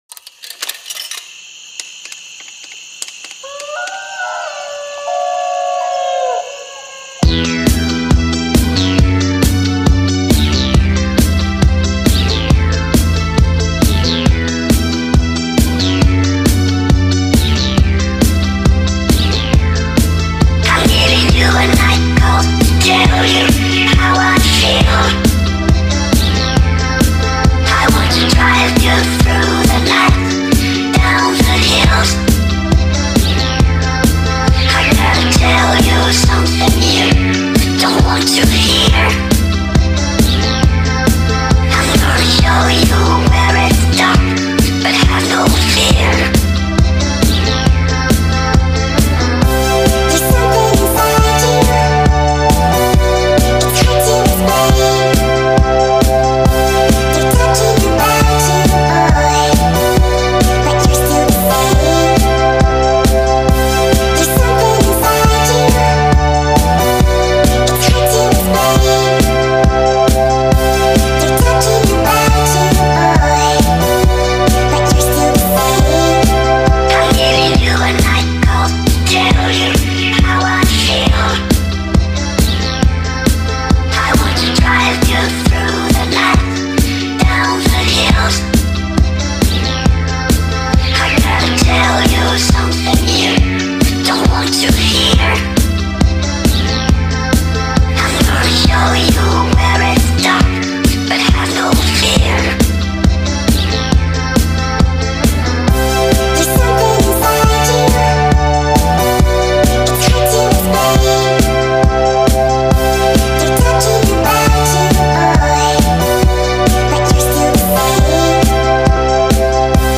در ورژن Sped Up و تند شده